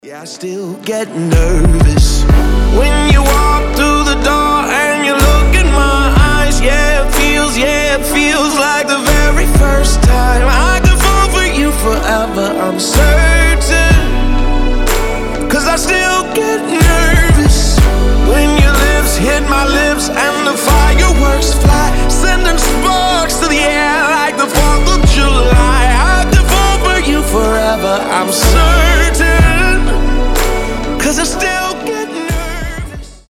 • Качество: 320, Stereo
красивый мужской голос
RnB